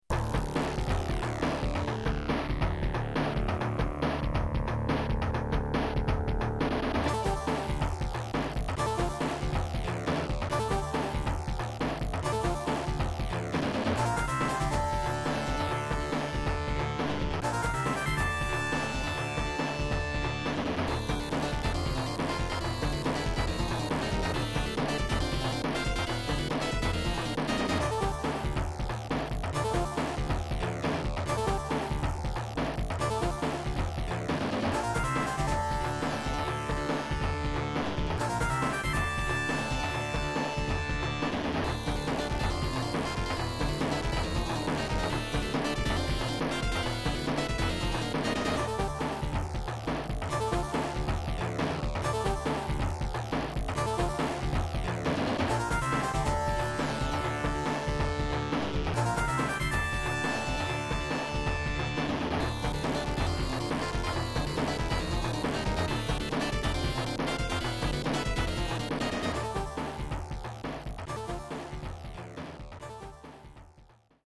All of this music is from the arcade version of the game.